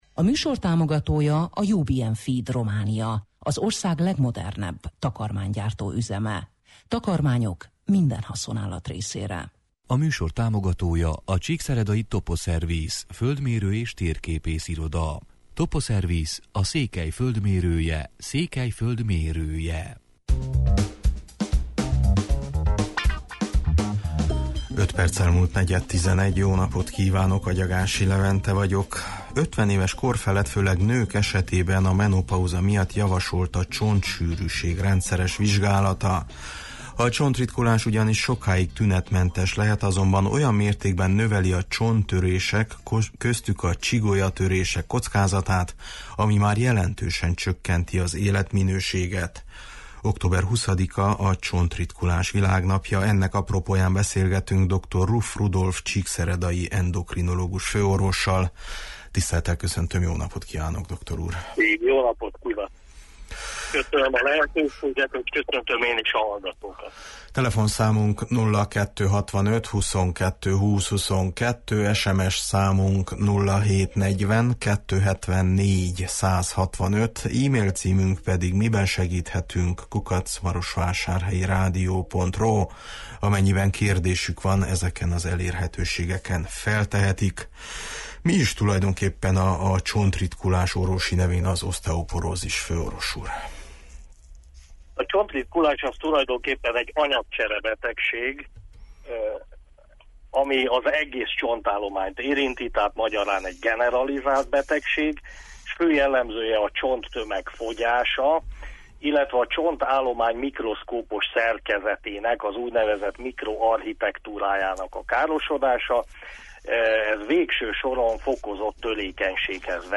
Ennek apropóján beszélgetünk